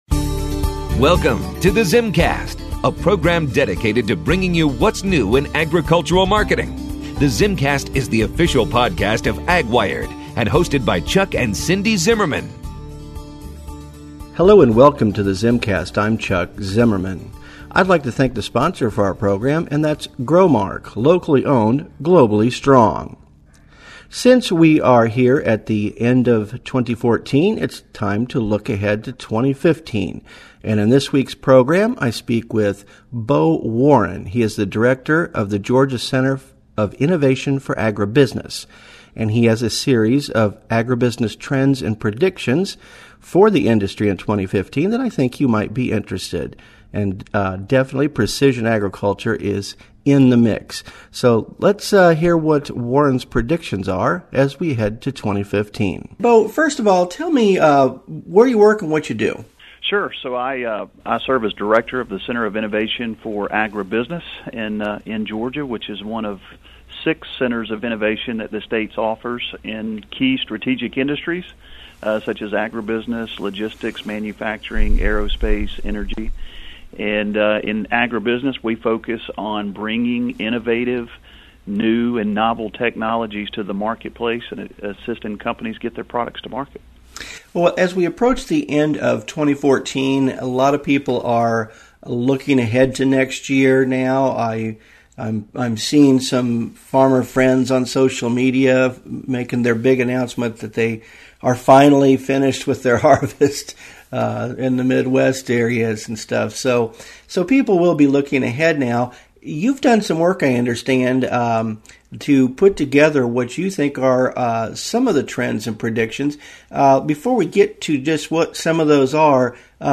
Agribusiness Trends and Predictions Subscribe to the ZimmCast podcast here.